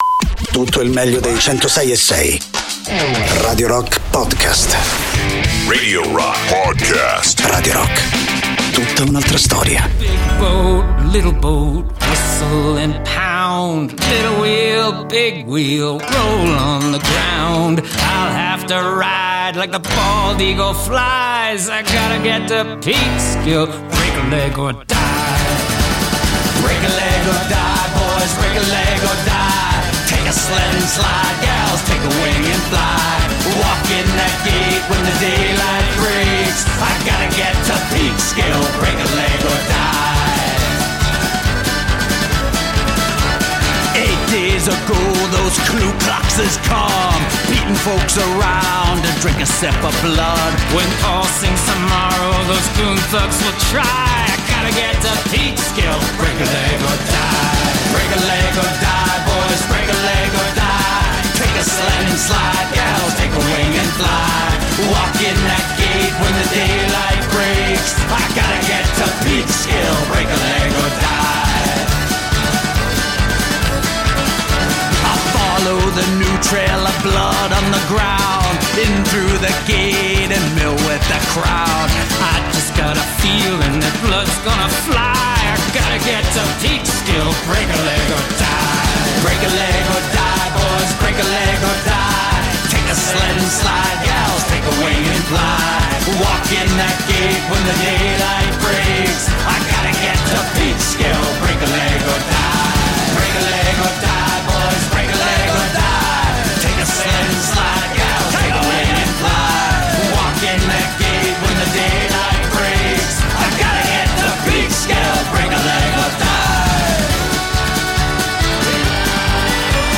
Interviste: Tim Brennan (Dropkick Murphys) (11-05-23)